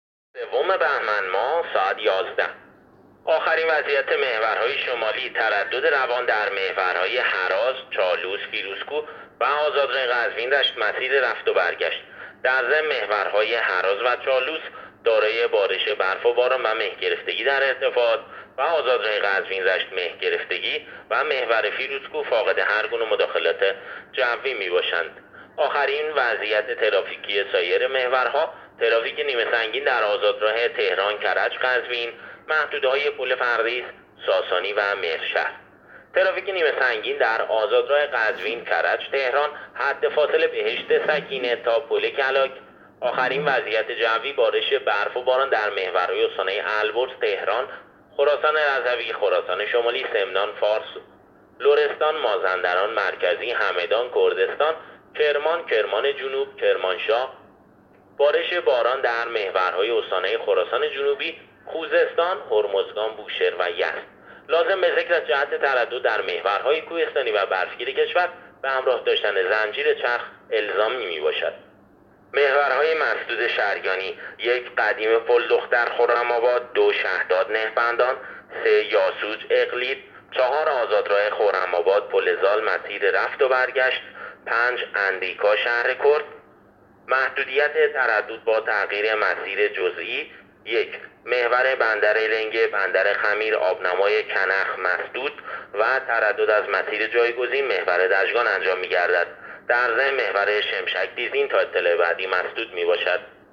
گزارش رادیو اینترنتی از آخرین وضعیت ترافیکی جاده‌ها تا ساعت ۱۱ پنجشنبه سوم بهمن‌ماه ۱۳۹۸